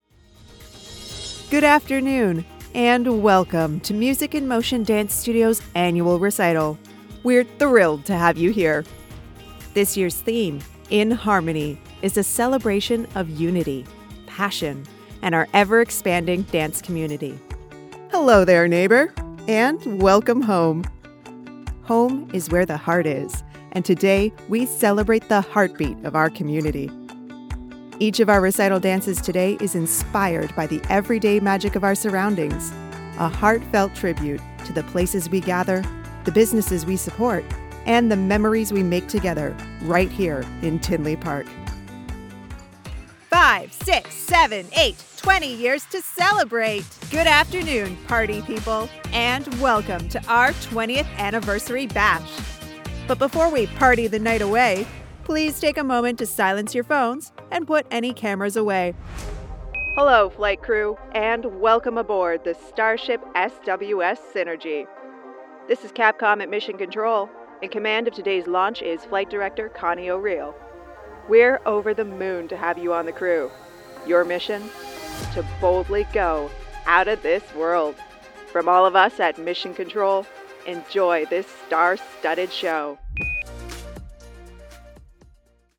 Englisch (Kanadisch)
Ankündigungen
Hauptmikrofon: Aston Spirit
Studio: Maßgeschallte, permanente Heimkabine (-79dB Geräuschpegel)
Im mittleren Alter